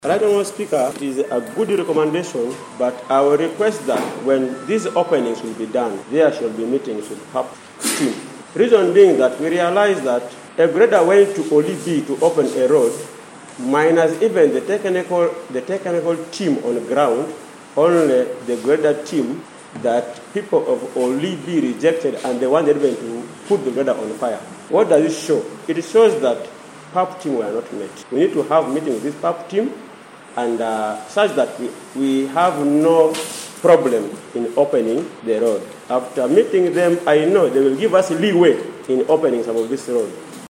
Ismail Lutale, a counselor representing Tanganyika Lower, appealed to his fellow councilors to engage in thorough consultations before embarking on this ambitious road opening project. He stressed the importance of seeking input from residents and stakeholders to ensure that the newly proposed roads align with the community's needs and expectations.